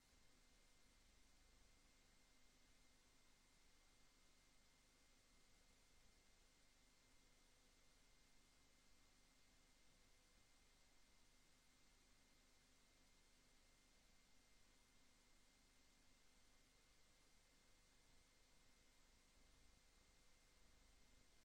Raadsvergadering Papendrecht 07 juli 2025 20:00:00, Gemeente Papendrecht
Raadzaal